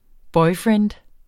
Udtale [ ˈbʌjˌfɹεːnd ]